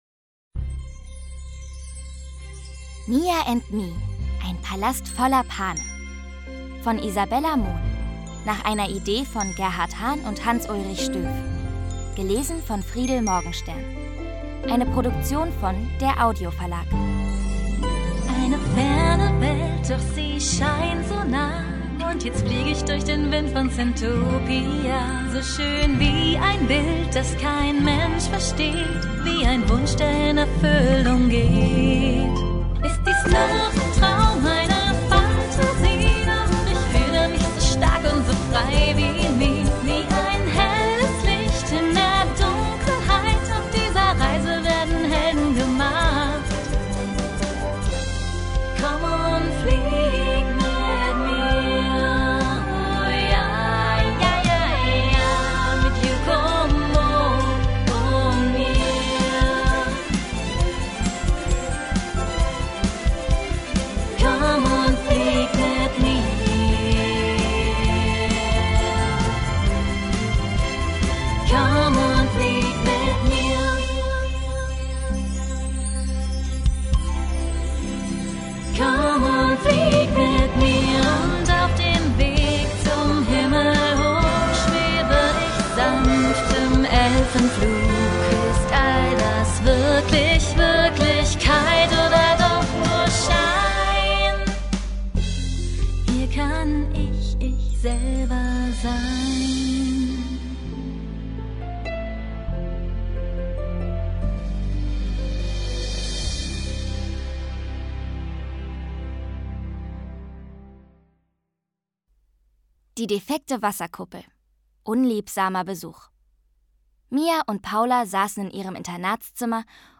Lesung mit Musik